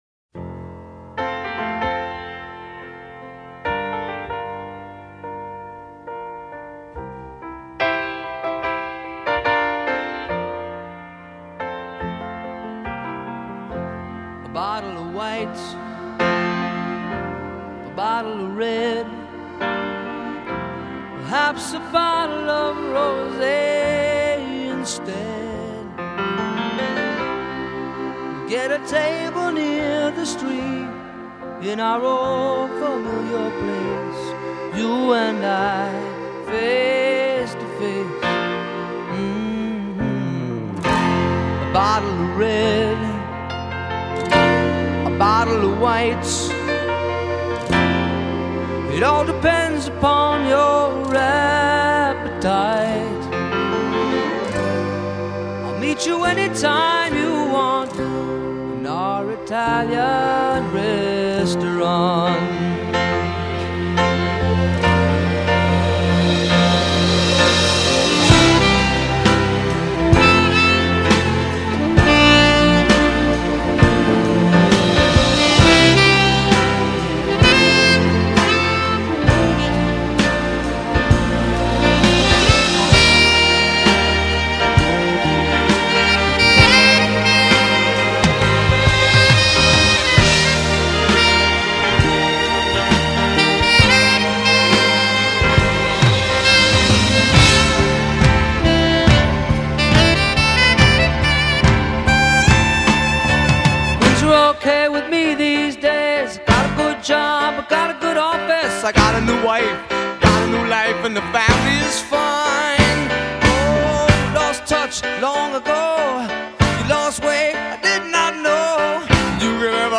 This seven and a half minute epic